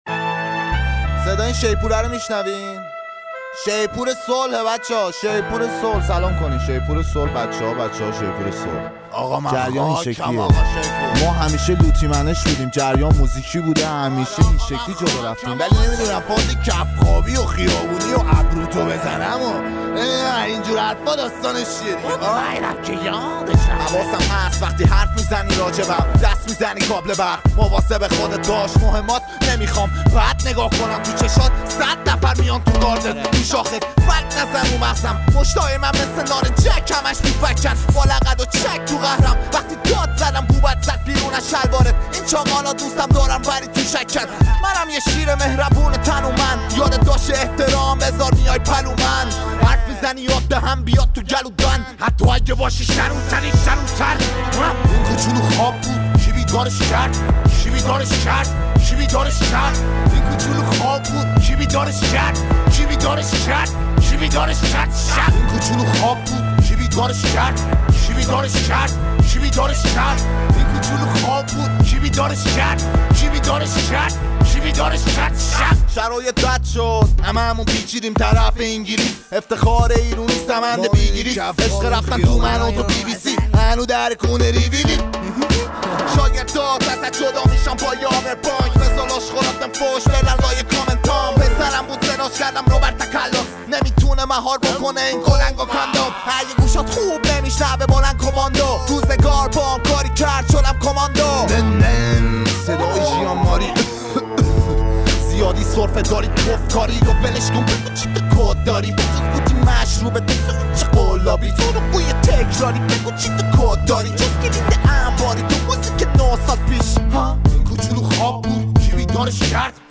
دیس بک